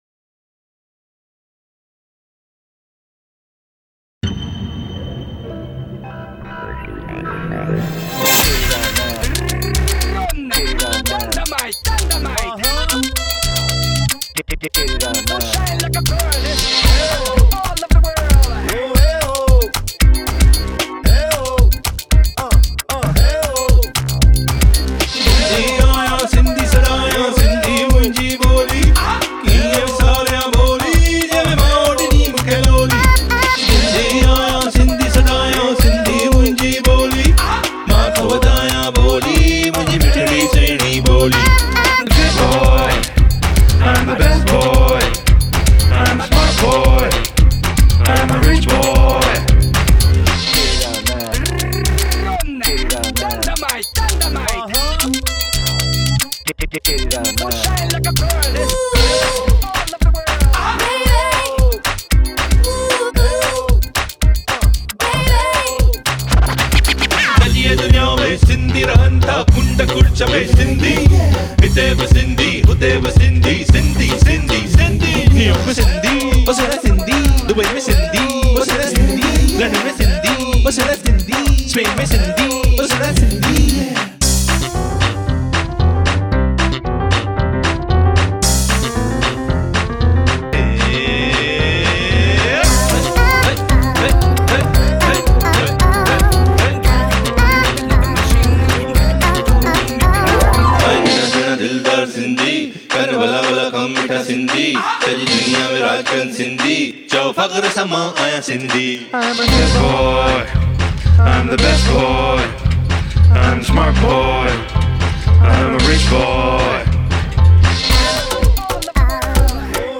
Rap Singer
Backing Vocals